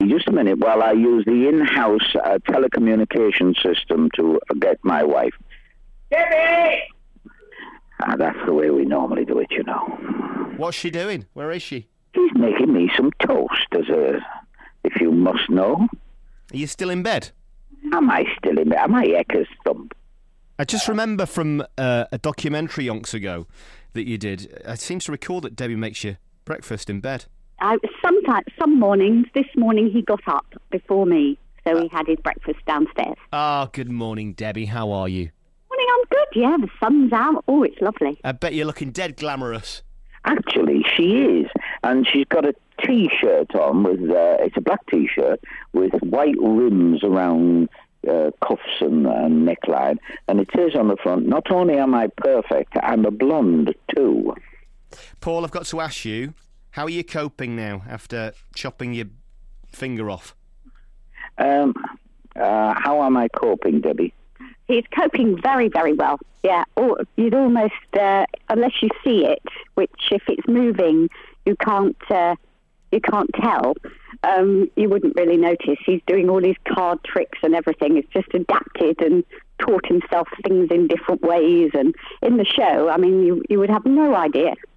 Paul Daniels and wife Debbie chat